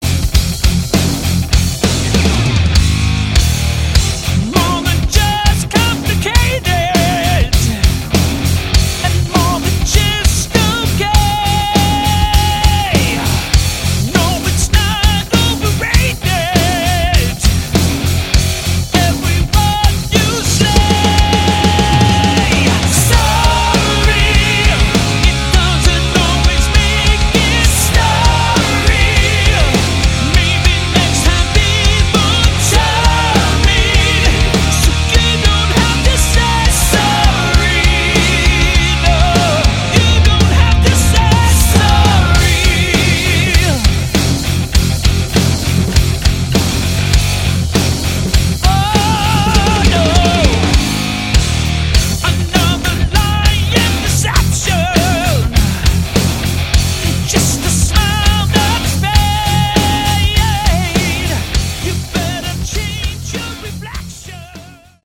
Category: Hard Rock
lead vocals, guitar
drums, background vocals